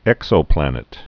(ĕksō-plănĭt)